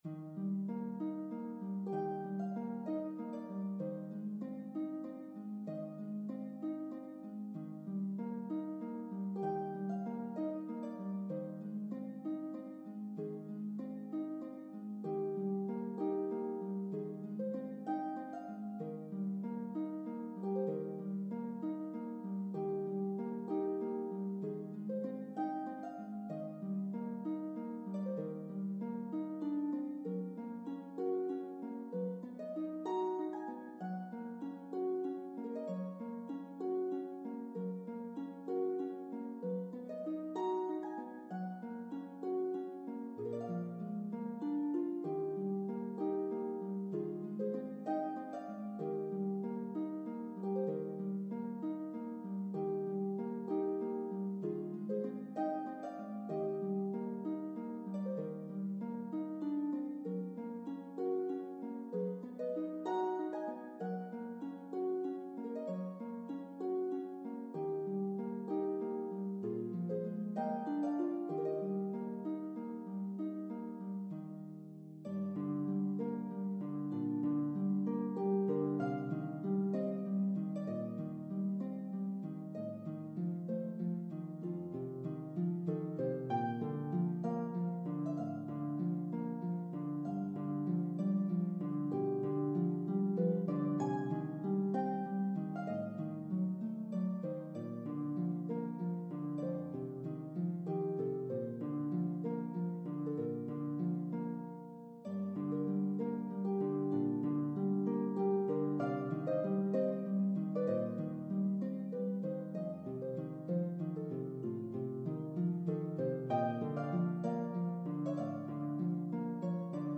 for harp